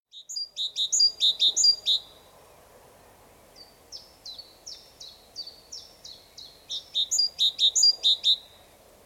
okmésange charbonnière_1tse5sh7lmlkn.mp3